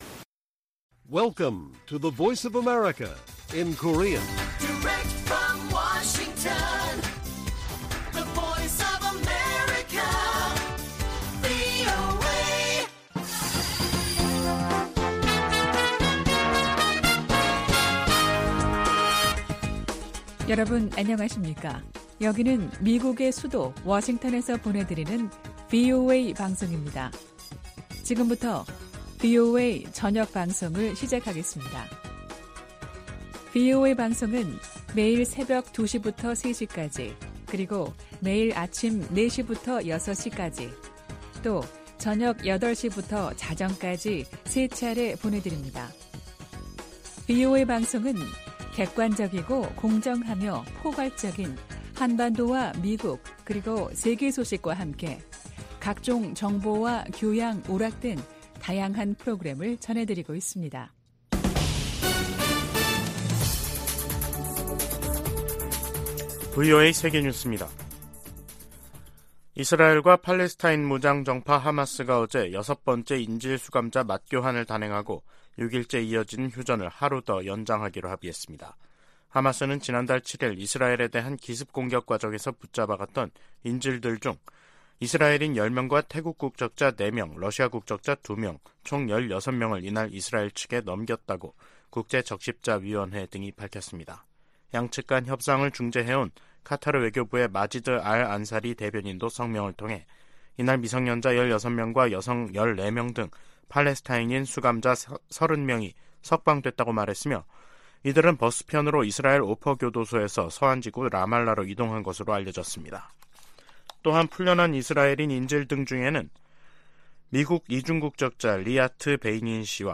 VOA 한국어 간판 뉴스 프로그램 '뉴스 투데이', 2023년 11월 30일 1부 방송입니다. 미국 재무부 해외자산통제실이 북한의 해킹 자금을 세탁한 가상화폐 믹서 업체 '신바드'를 제재했습니다. 북한이 제도권 금융기관에서 암호화폐 인프라로 공격 대상을 변경했다고 백악관 고위 관리가 밝혔습니다. 북한-러시아 군사 협력에 제재를 부과하도록 하는 법안이 미 하원 외교위원회를 만장일치 통과했습니다.